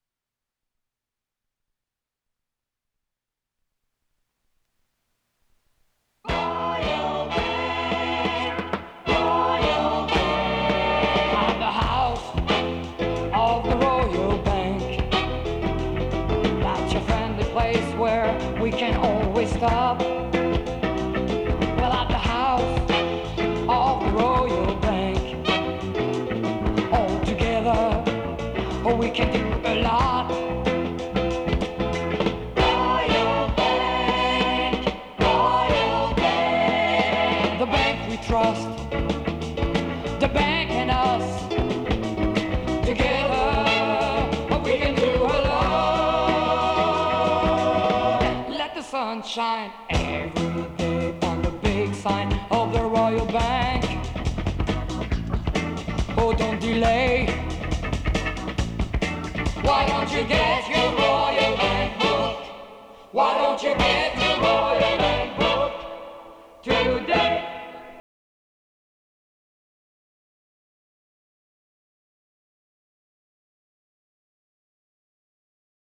Enregistré en Angleterre, Olympic Sound Studio, Barnes.